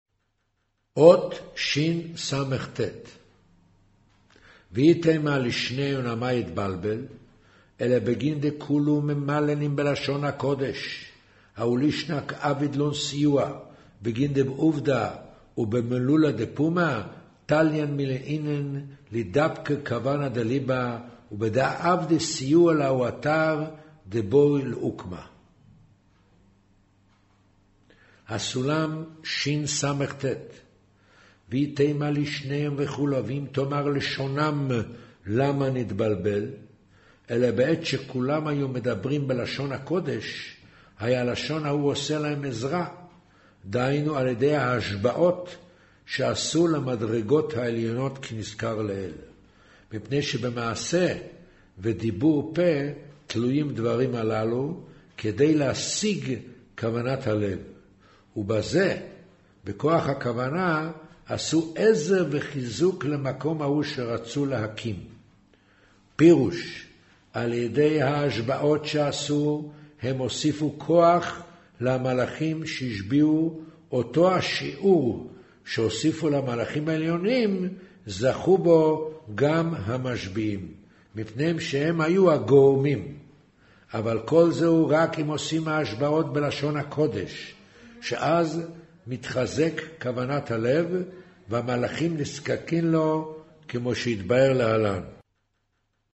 אודיו - קריינות זהר, פרשת נח, מאמר ויאמר ה' הן עם אחד